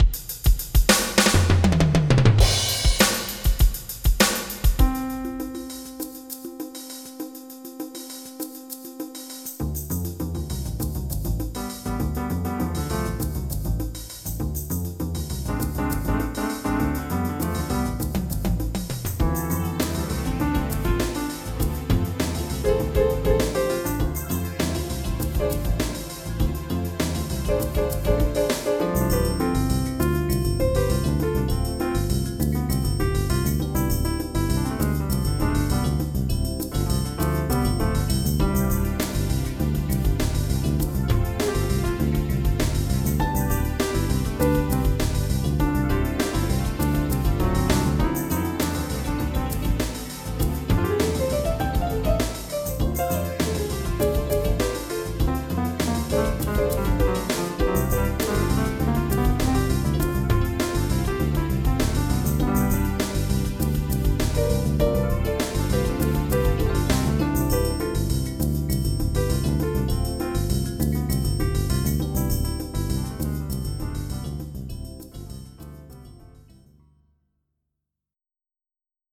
MIDI Music File
General MIDI (type 1)